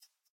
rabbit_idle3.ogg